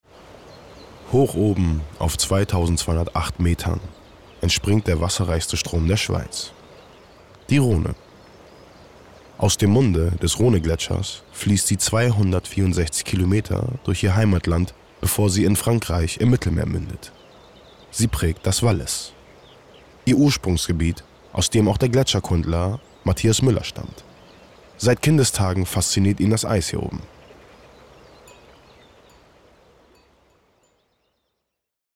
markant, dunkel, sonor, souverän, plakativ
Mittel minus (25-45)